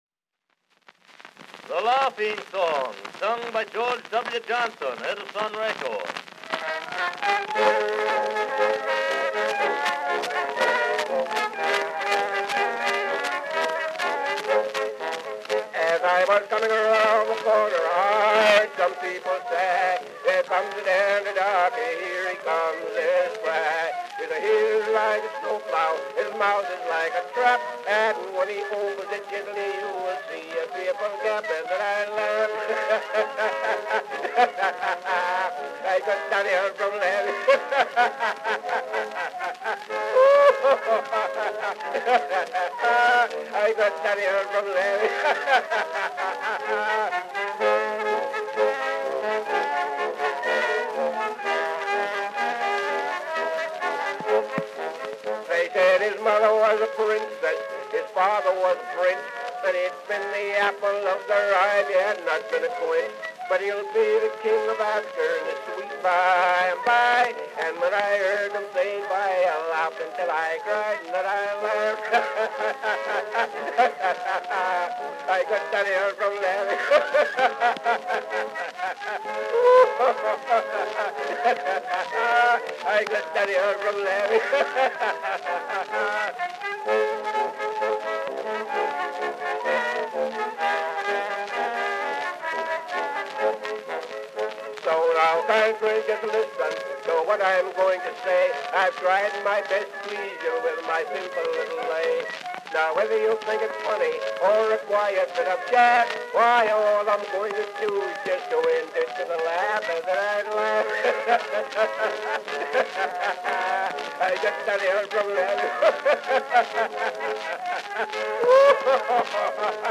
Born a slave, George W. Johnson became the first African American recording artist in 1890.
The total sales of his wax cylinders between 1890 and 1895 are estimated to have been at 25,000 to 50,000, each one recorded individually by Johnson.